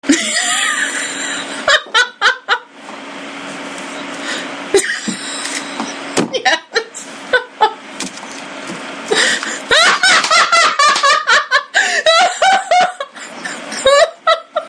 I have the most infectious laugh on the planet.
LaughMP4.mp3